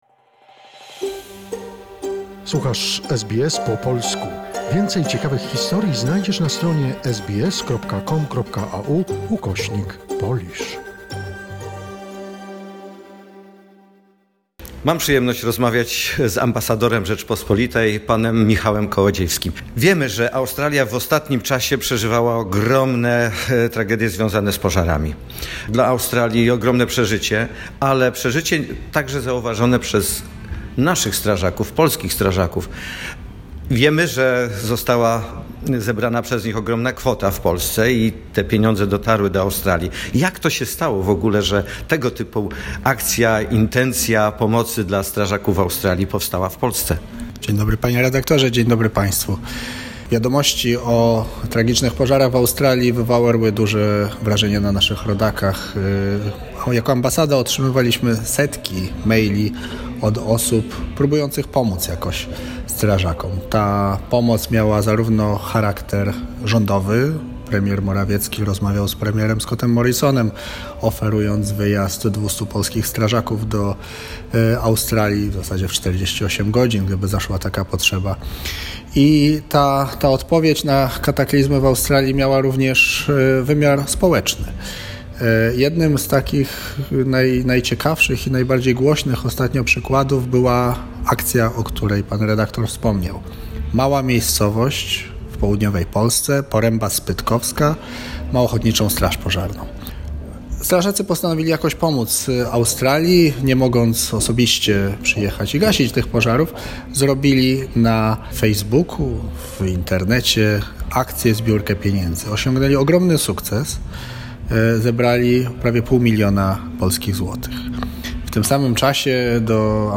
O akcji zbierania pieniędzy polskich strażaków na ofiary pozarów w Australii mówi Ambasador RP w Australii Michał Kołodziejski.